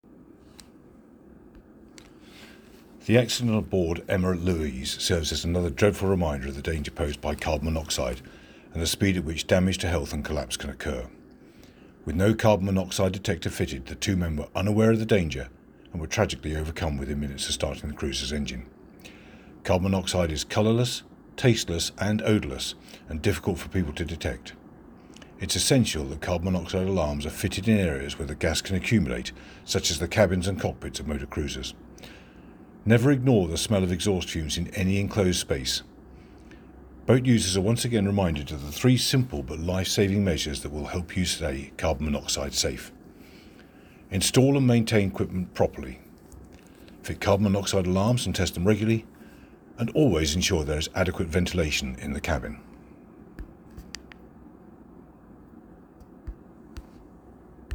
Andrew Moll, MAIB Chief Inspector statement